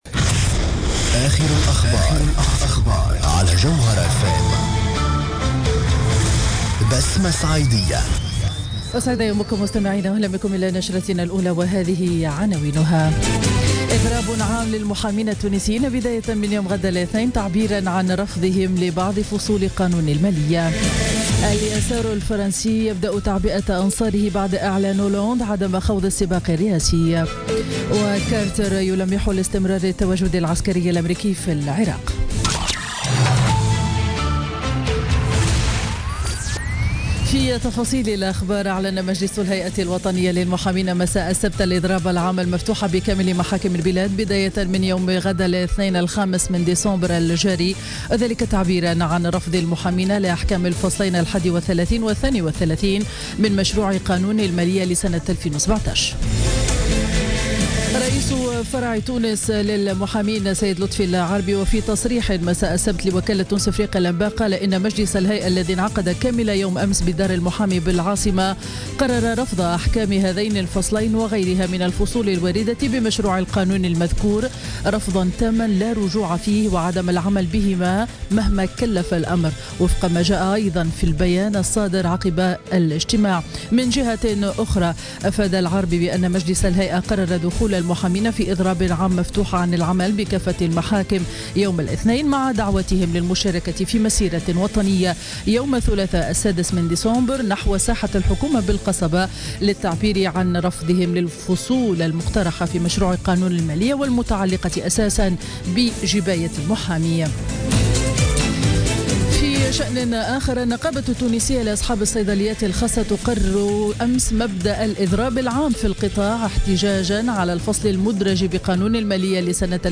نشرة أخبار السابعة صباحا ليوم الأحد 4 ديسمبر 2016